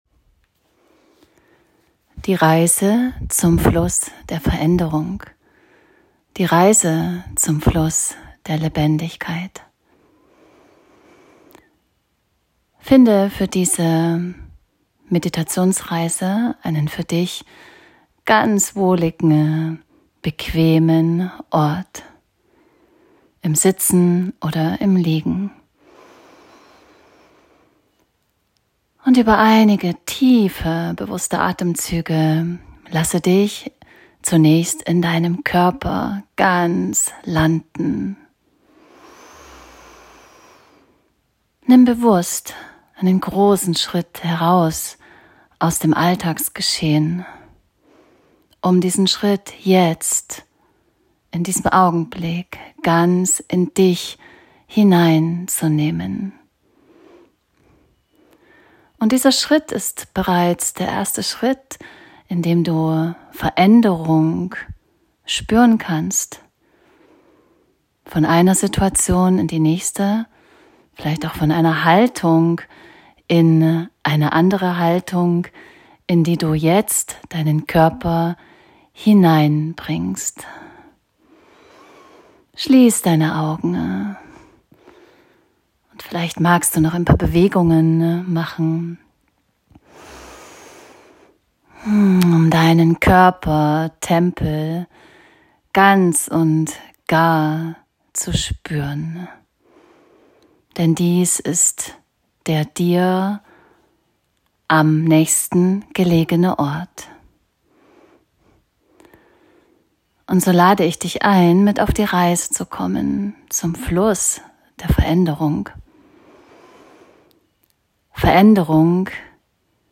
Meditation "Fluss der Veränderung" ~ Sonnen-Herz💛 Podcast